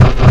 scratch 5
effect hip hop noise noises rap record scratch sound effect free sound royalty free Sound Effects